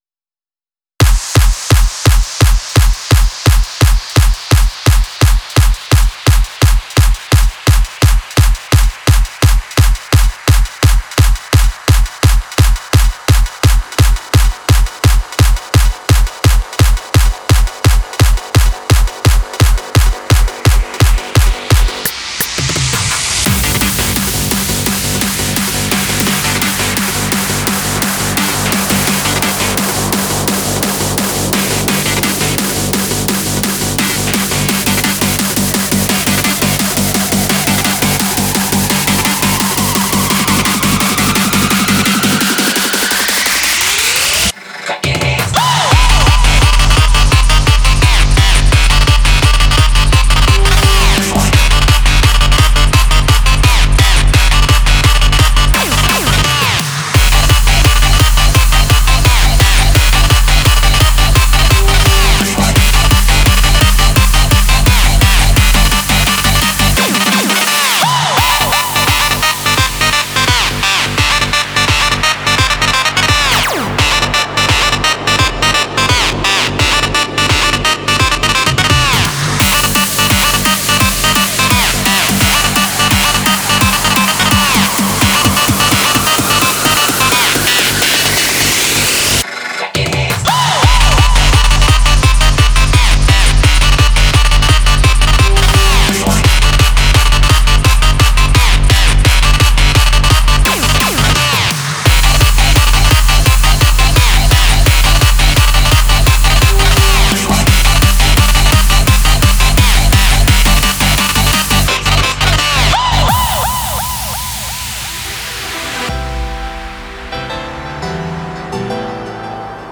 data/localtracks/Japanese/J-Core